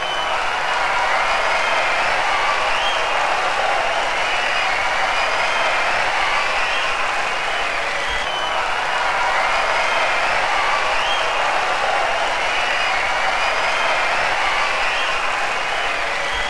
crowdlng.ogg